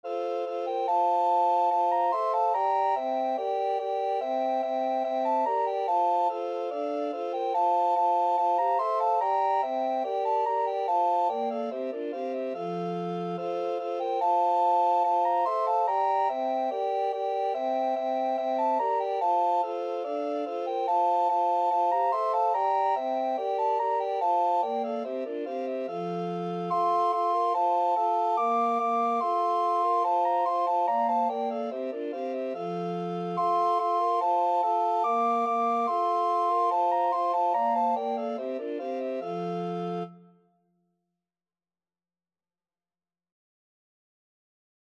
Free Sheet music for Recorder Quartet
Soprano RecorderAlto RecorderTenor RecorderBass Recorder
C major (Sounding Pitch) (View more C major Music for Recorder Quartet )
4/4 (View more 4/4 Music)
Classical (View more Classical Recorder Quartet Music)